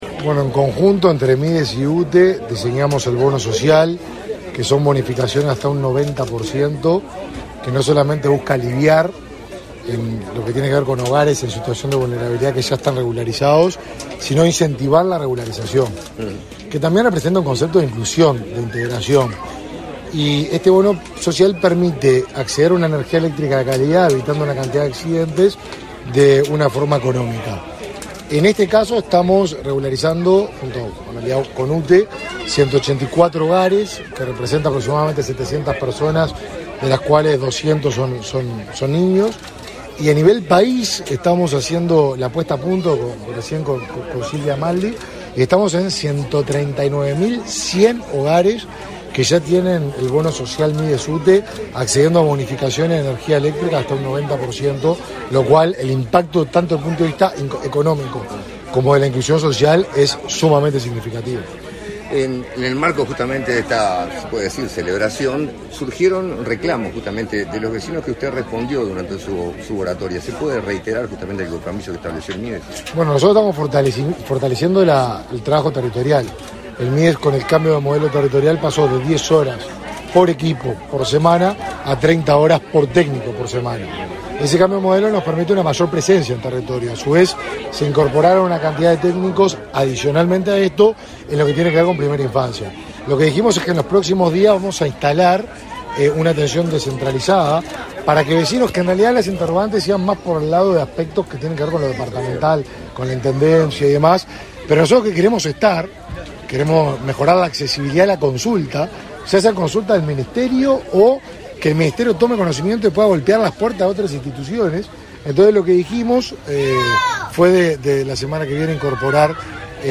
Declaraciones a la prensa del ministro del Mides, Martín Lema
Declaraciones a la prensa del ministro del Mides, Martín Lema 09/12/2022 Compartir Facebook X Copiar enlace WhatsApp LinkedIn Tras participar en la inauguración de obras de electrificación en el barrio Santa Teresa, en Montevideo, este 9 de diciembre, el titular del Ministerio de Desarrollo Social (Mides) realizó declaraciones a la prensa.